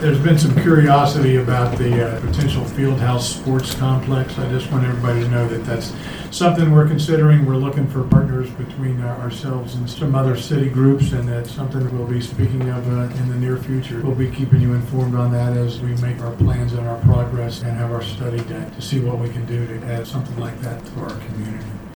At the end of Tuesday night’s Cumberland Mayor and City Council meeting, the City Council announced that they are considering bringing a field house sports complex to the community.  Mayor Ray Morriss said the City is currently looking for partners and will report progress as more information becomes available…